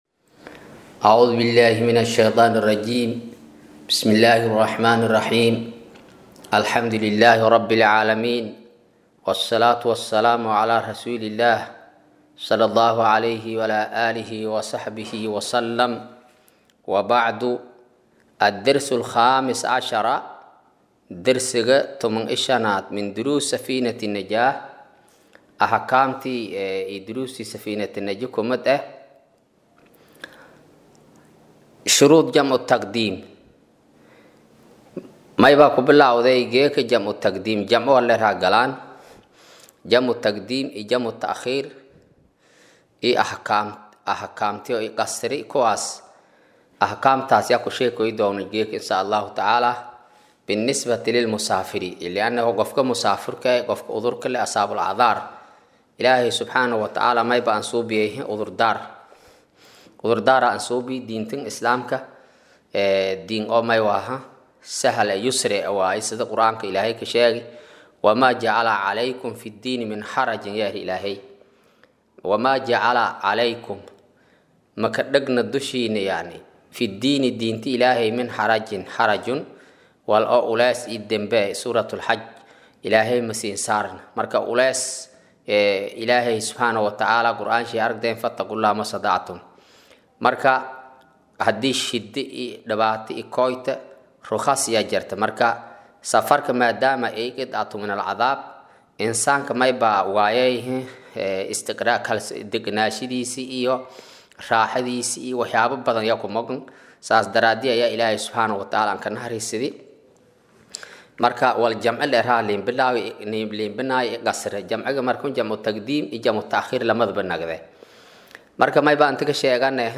Maqal: Casharka Safiinatu Najaa “Darsiga 15aad”